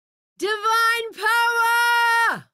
divine power sound effects
divine-power